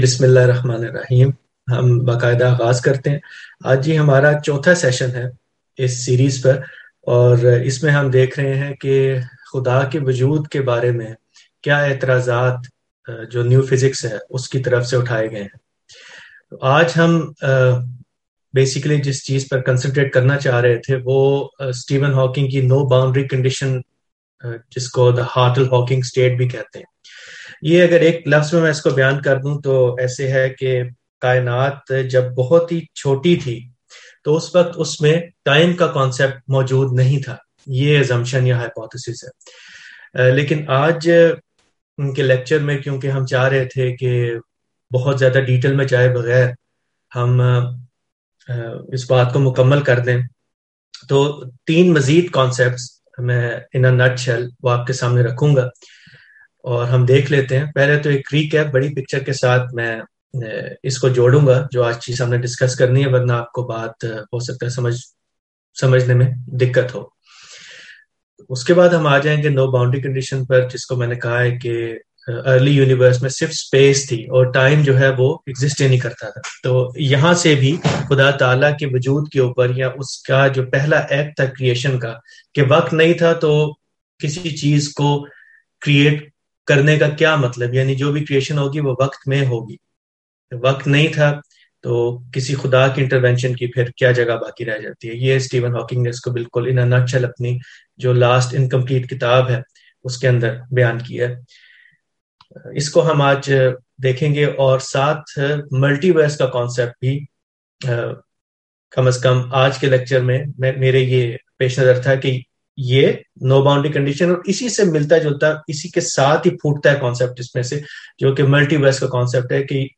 In the fourth lecture, I introduce and evaluate Hawking and Hartle's ‘no boundary proposal’. The proposal basically removes the concept of time in the primordial universe, thereby challenging any temporal act of divine creation. I also allude to the multiverse hypothesis and comment on it from scientific, philosophical, and theistic perspective.